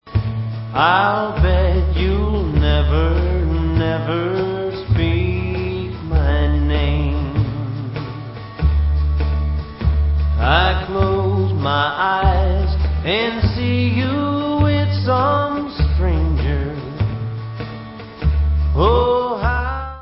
Counrty swing with rockabilly flavors